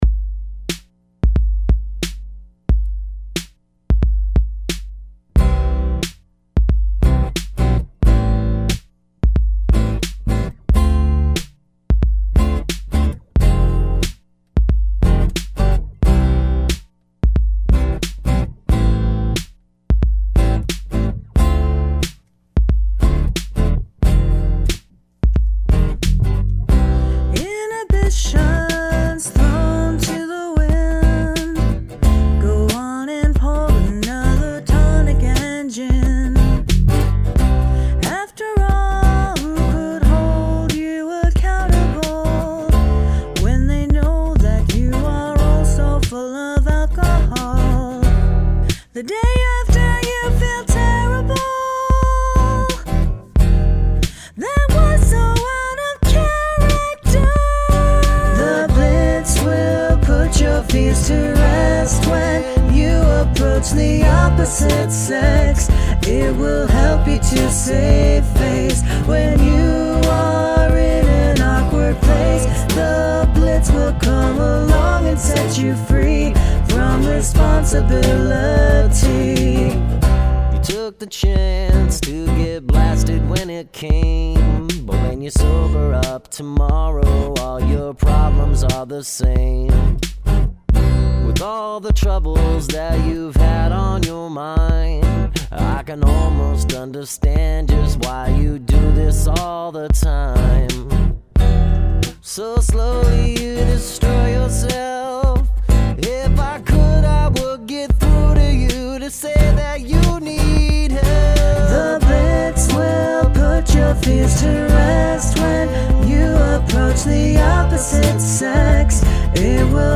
Use of choral voices
The backing vox are really cool.
You both have great voices.